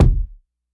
SBV_V12_Kick_009.wav